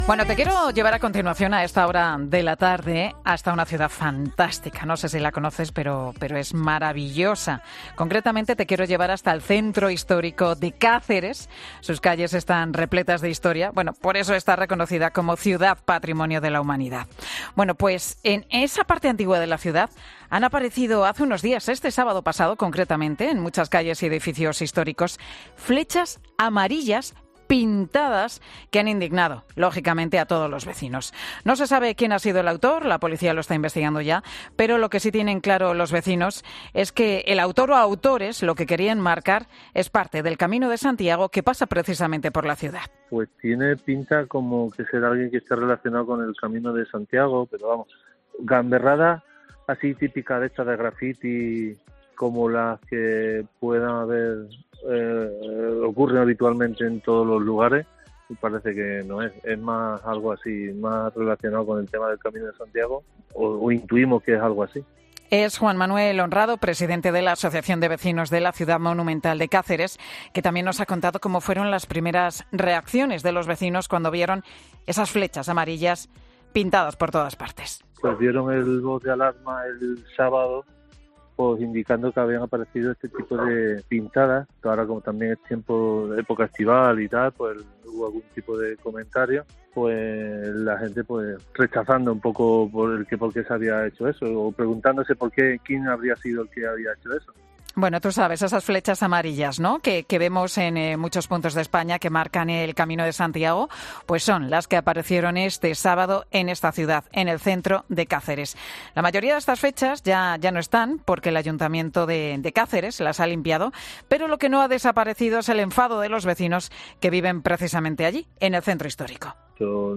Escucha la entrevista completa a la concejala de Turismo de Cáceres sobre la aparición de flechas pintadas